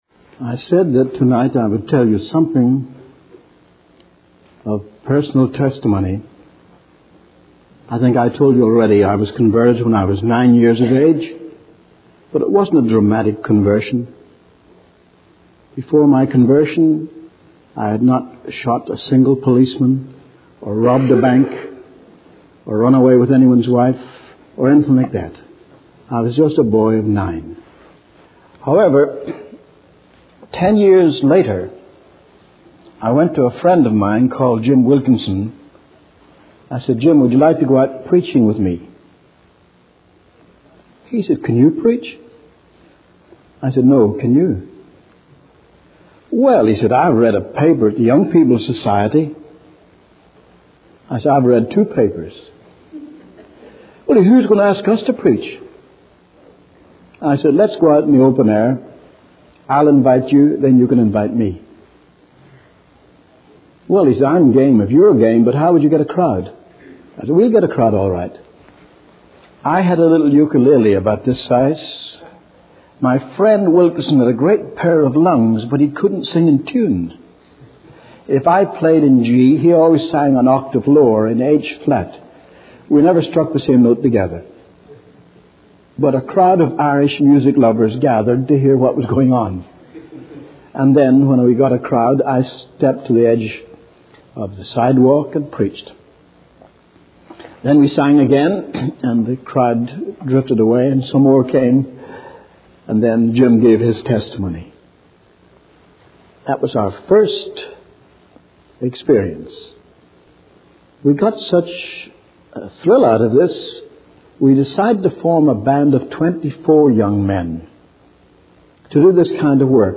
In this sermon, the speaker shares a personal testimony of his conversion and his journey into preaching. He recounts how he and a friend decided to go out and preach, despite having no experience.